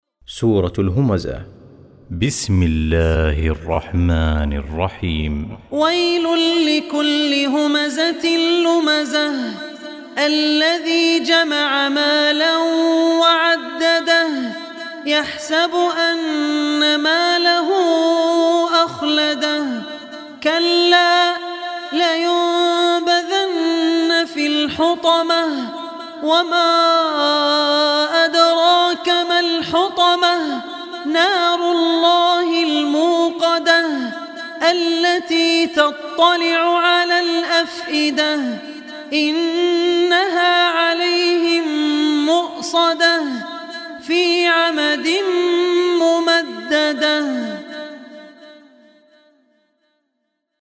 Surah Humazah Recitation by Al Ossi
Surah Humazah , listen online mp3 tilawat / recitation recited by Sheikh Abdur Rehman Al Ossi.